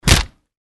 Звуки ударов руками, ногами
На этой странице собраны разнообразные звуки ударов: рукопашные схватки, удары по лицу, драки и воздействие на предметы.